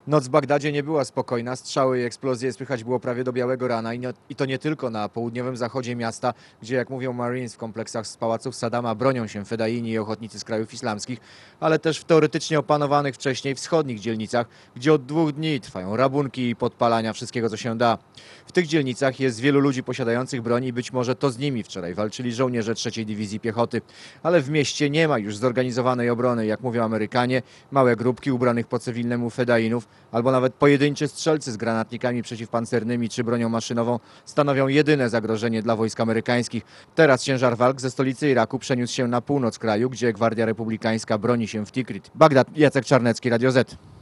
Posłuchaj relacji specjalnego wysłannika